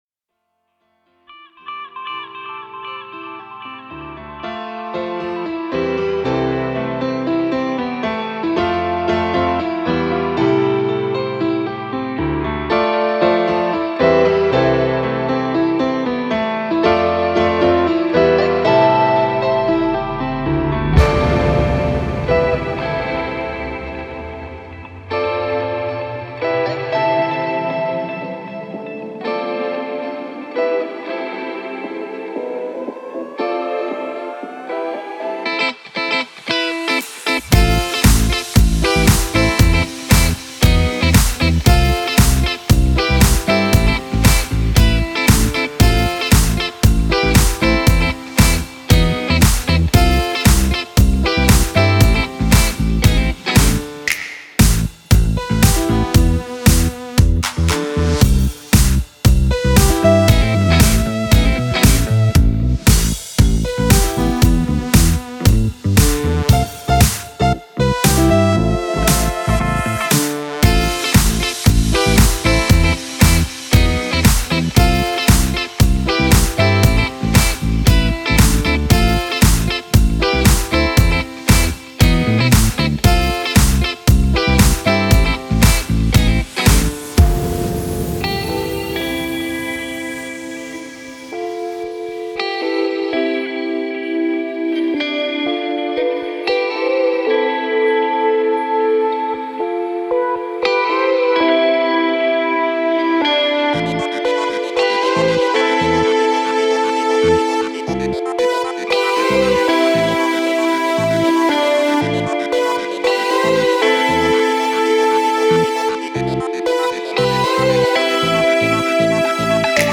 Guitars
Bass